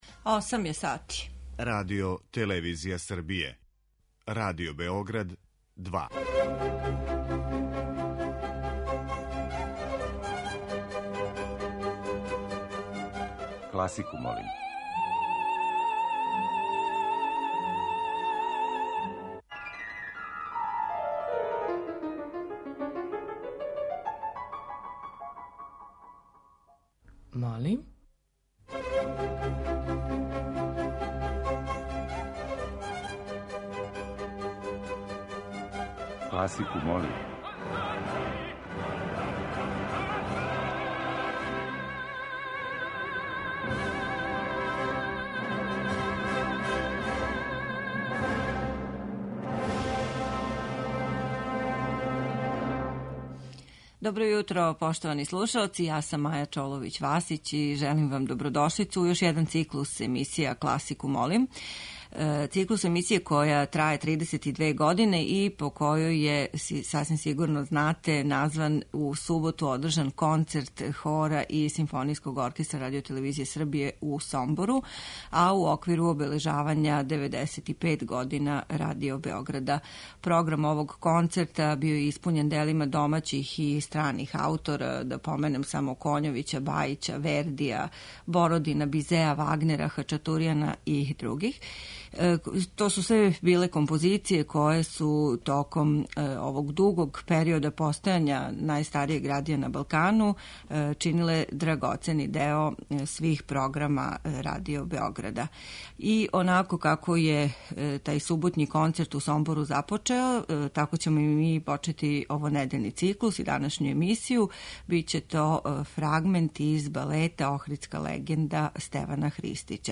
Уживо вођена емисија, окренута широком кругу љубитеља музике, разноврсног је садржаја.